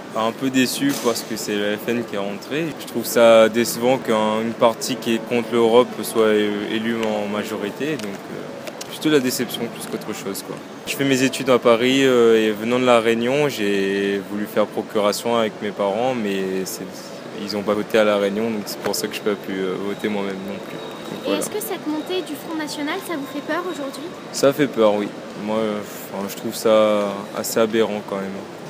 Ambiance et micro-trottoir…
Ambiance à la gare Saint-Lazare (Paris), le lieu de plus forte affluence du pays chaque matin.